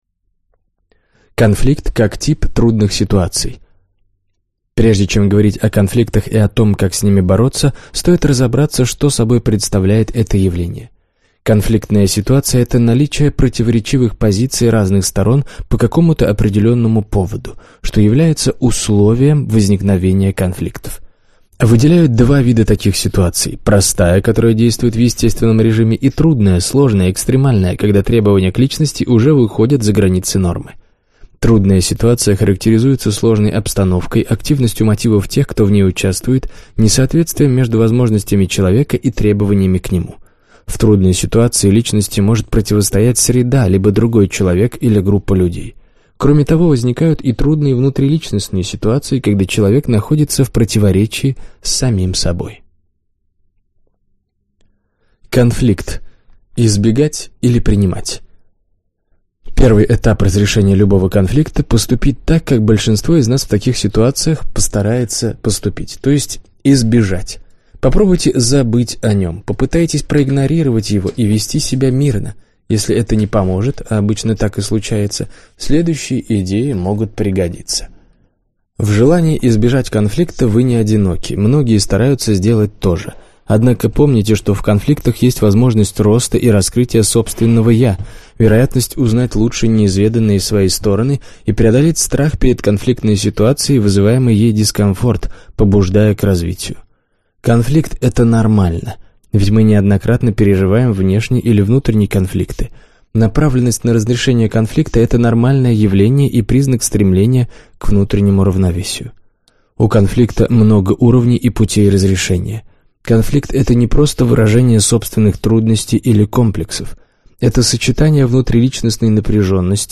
Aудиокнига Победителей не судят.